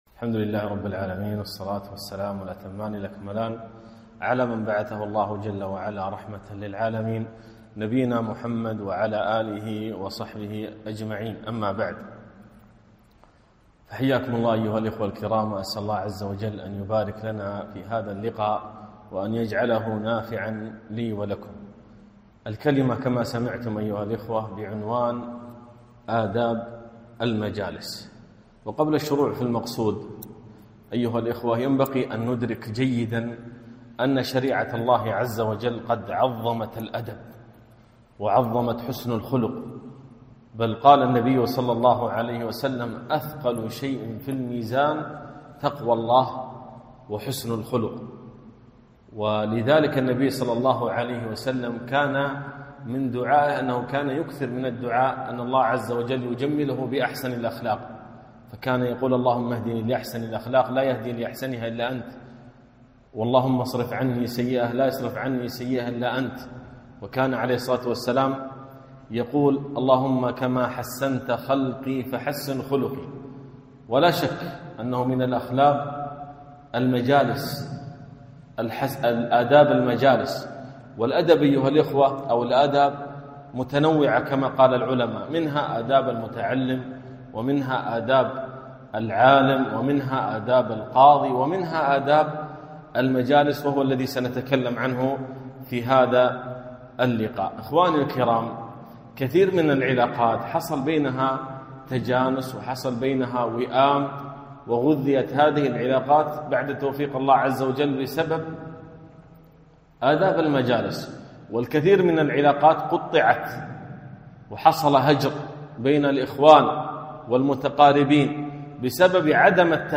محاضرة - آداب المجالس